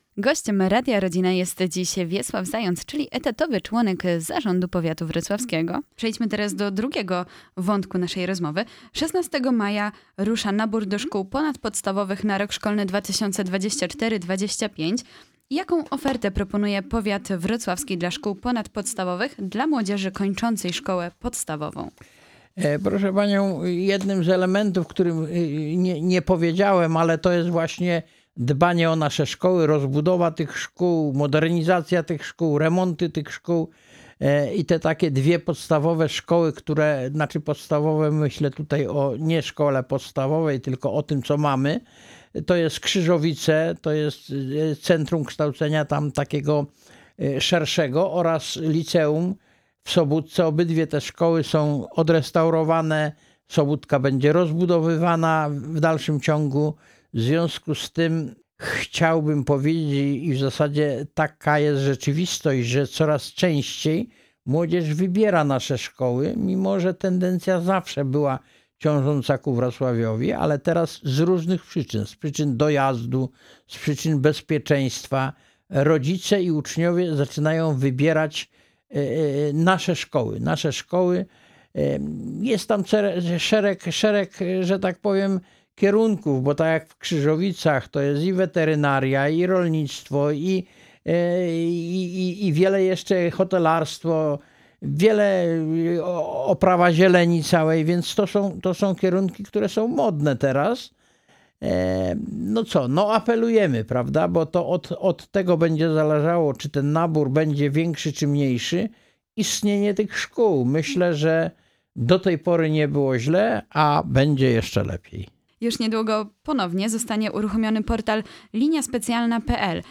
W studiu Radia Rodzina gościliśmy dziś Wiesława Zająca, który podsumował VI kadencję Rady Powiatu Wrocławskiego, opowiedział o perspektywach na kolejną, VII kadencję.